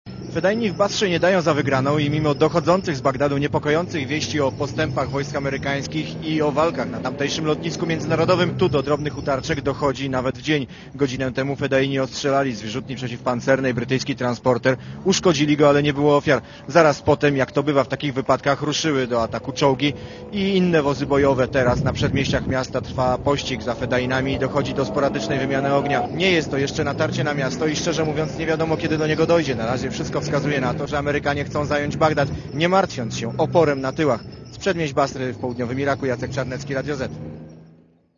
Spod Basry mówi wysłannik